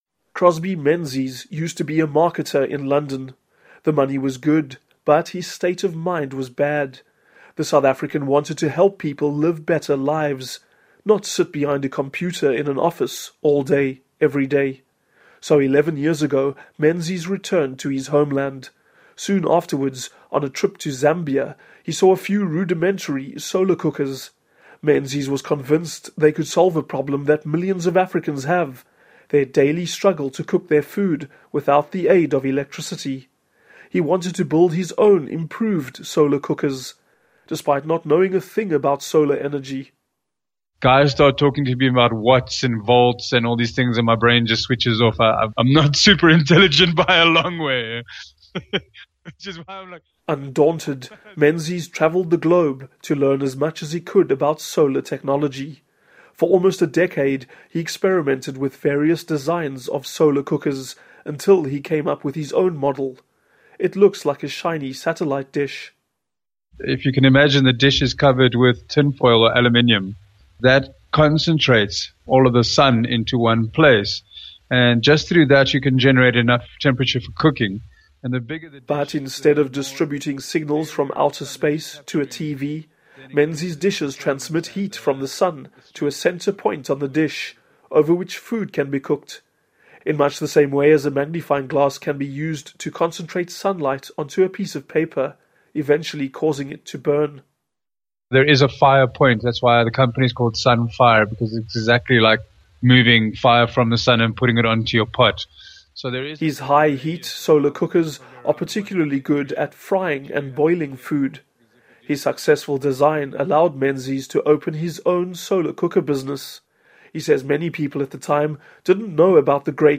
report on the Sunfire Cooker by Voice of America (VOA News)